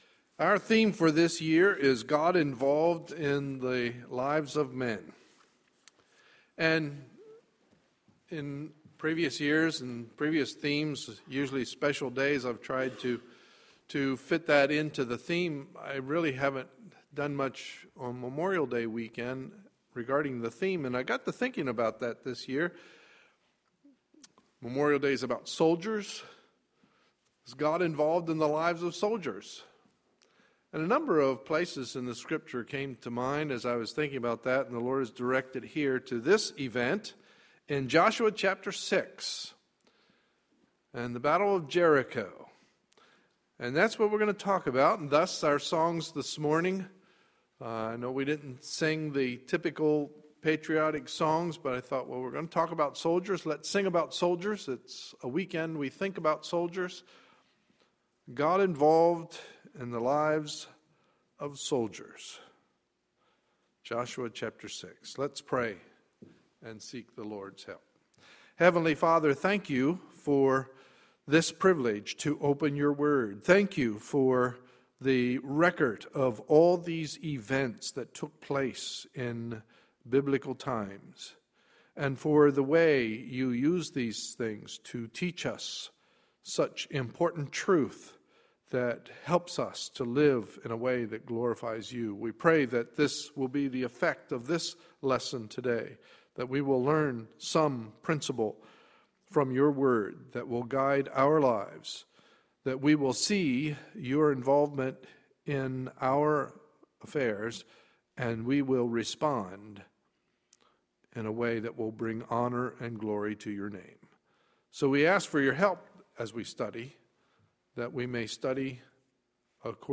Sunday, May 26, 2013 – Morning Service